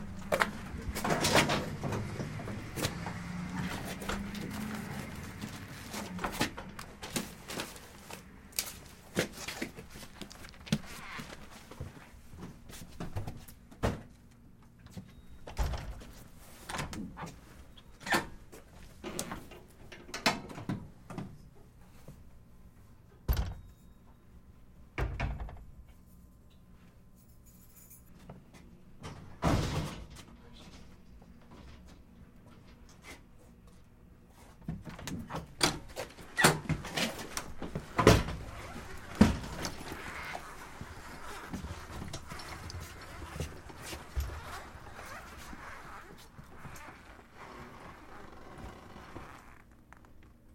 随机 "金属送货车在推过双门进入商店时发出砰砰声。
描述：金属交付小车爆炸，通过双门推入商店bgsound.flac
标签： 小车通过 配送 金属 到人 双人 各地
声道立体声